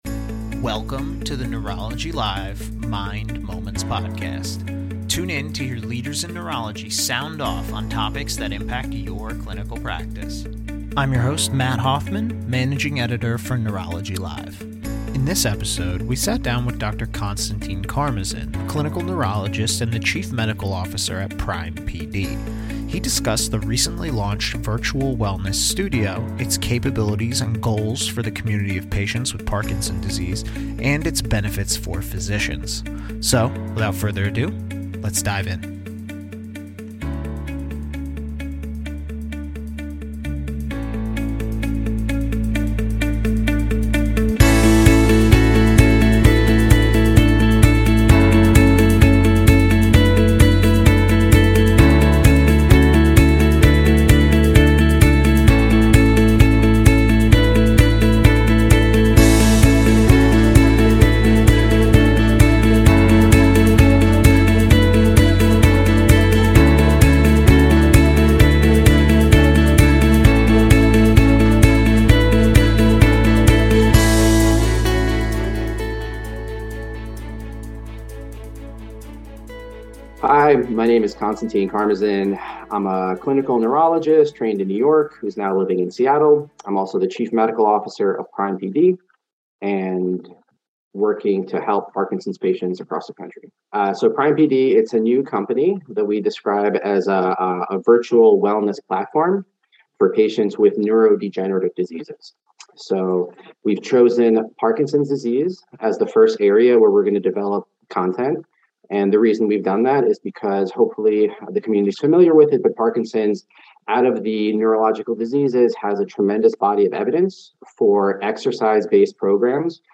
Tune in to hear leaders in neurology sound off on topics that impact your clinical practice.